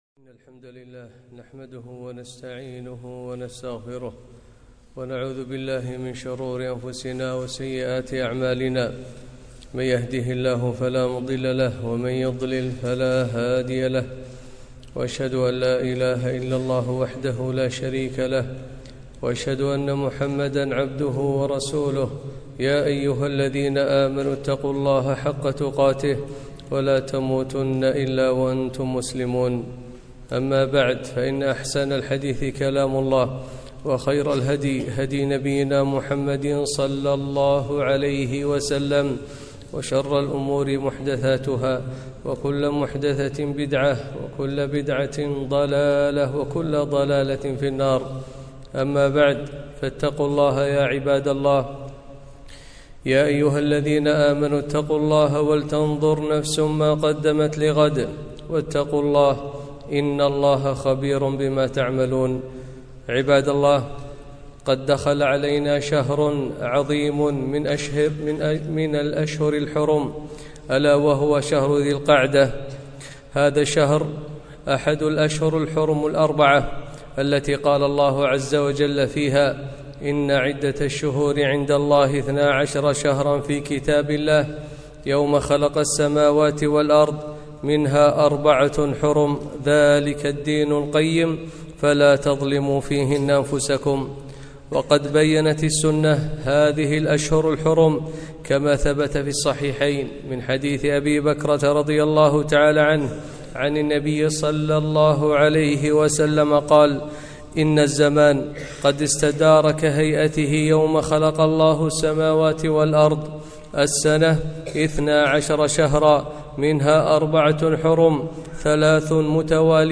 خطبة - فضل شهر الله المحرم ذي العقدة